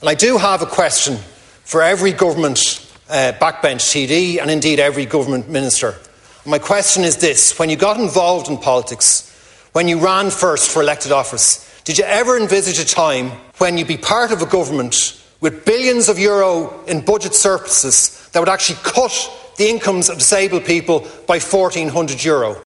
Speaking in the Dáil this afternoon, TD Cian O’Callaghan says funding is available to provide additional supports for people with disabilities: